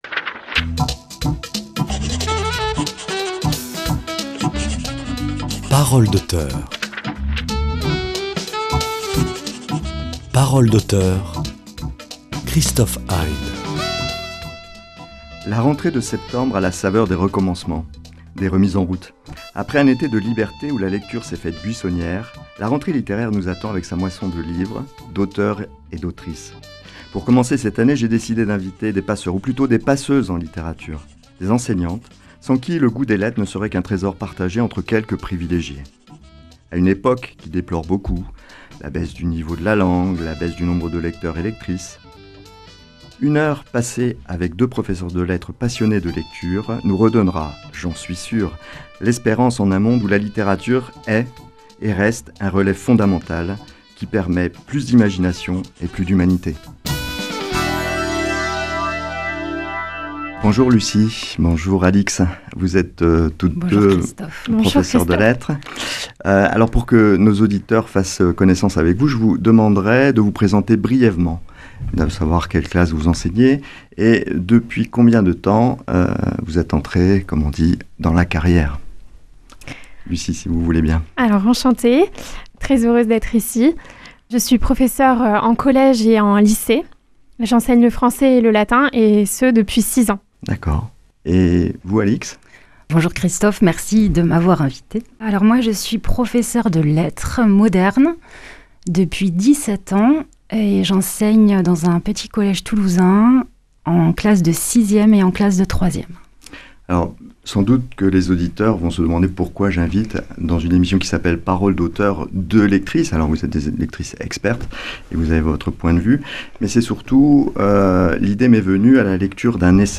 Ce goût est né parfois d’une transmission familiale, mais il passe souvent par l’école et la rencontre d’un ou d’une enseignante. À l’heure où les élèves reprennent le chemin de l’école, nous avons invité aux micros de notre émission deux professeures de lettres qui parlent de leur passion de la lecture.